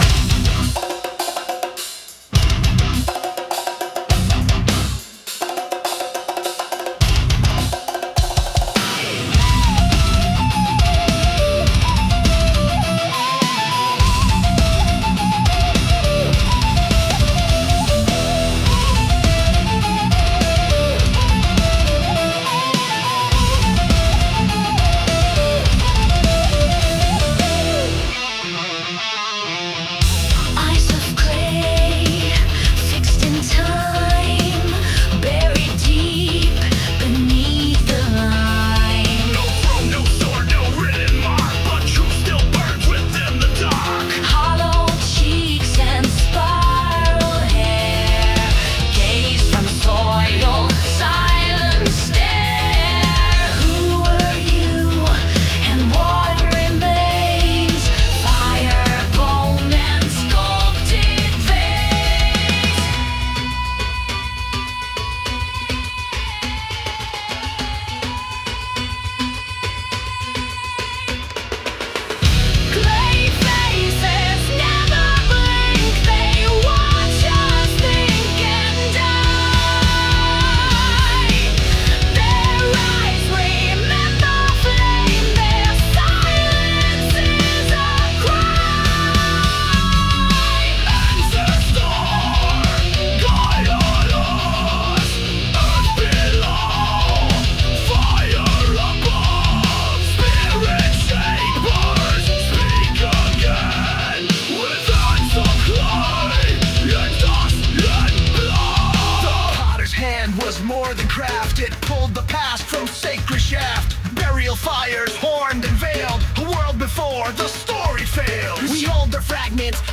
power metal tapestry